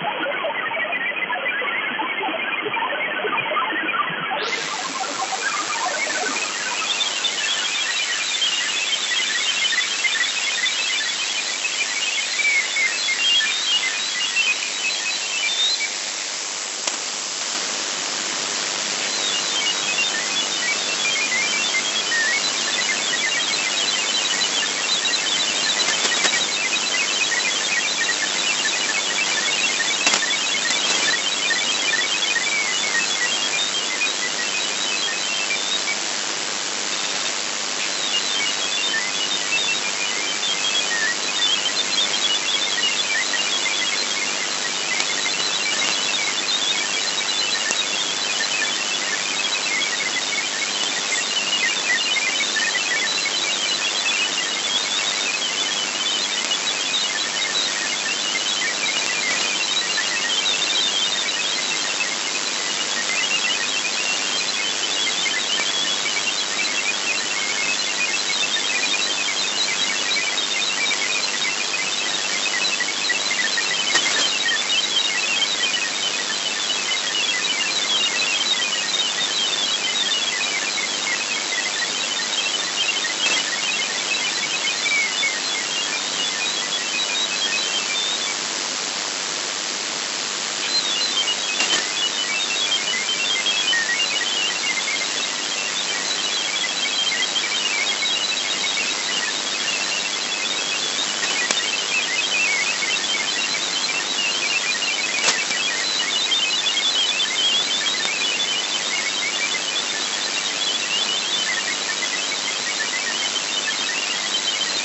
MFSK
Начало » Записи » Радиоcигналы классифицированные
Запись МФСК